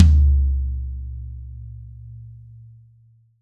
Index of /90_sSampleCDs/Best Service - Real Mega Drums VOL-1/Partition G/DRY KIT 1 GM